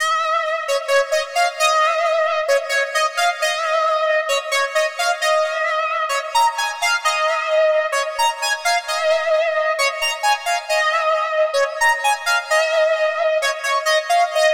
Saw Attack 132 BPM .wav